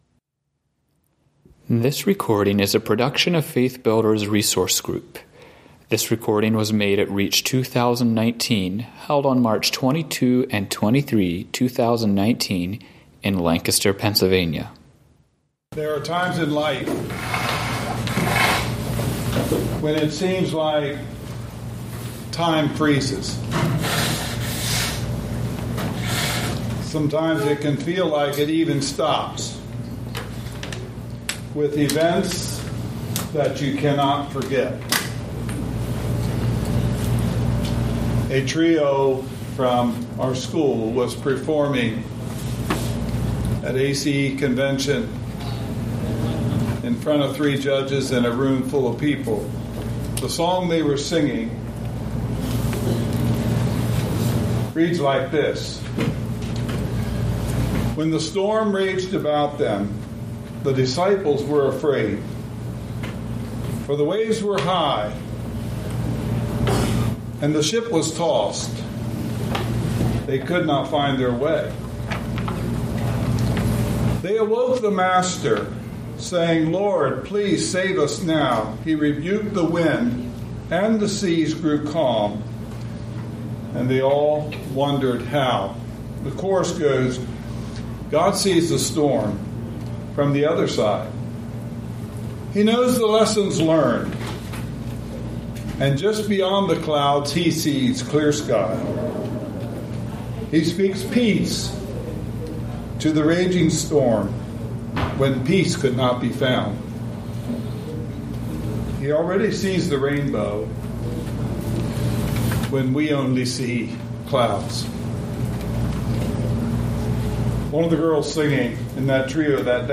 Home » Lectures » Healing for Hurting Hearts